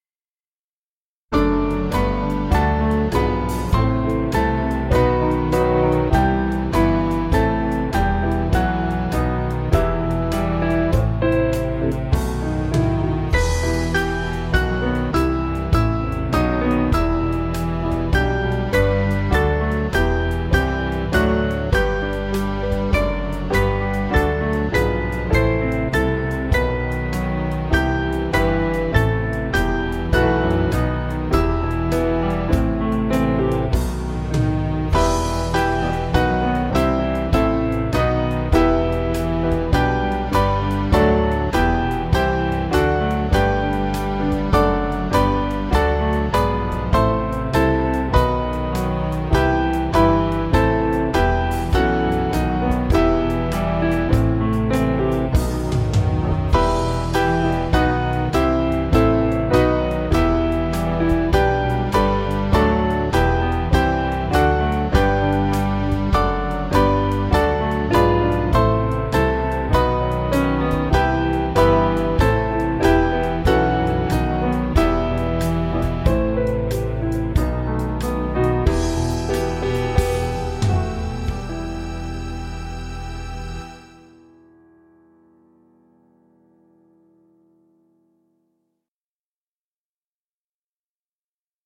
Small Band
(CM)   3/Em 466.6kb